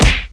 player_nunchuck_hit.ogg